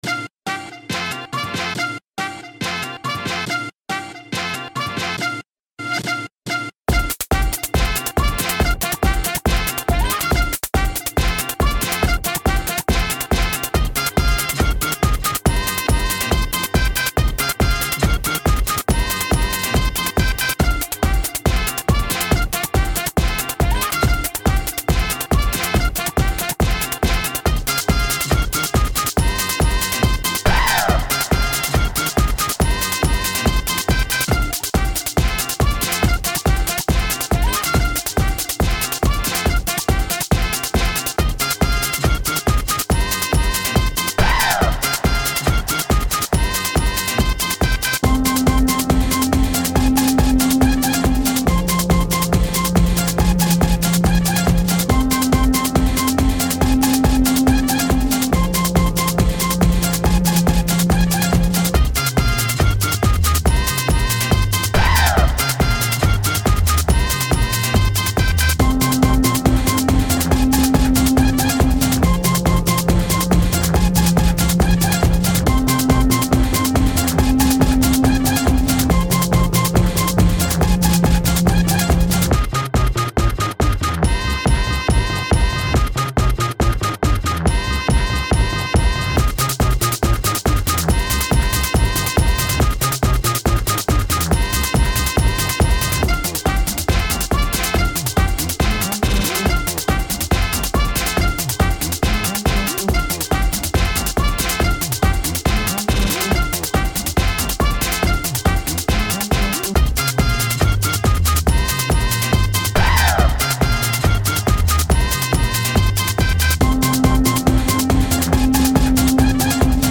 :'(Electro Jazz'ish stuff.
Genre Electronica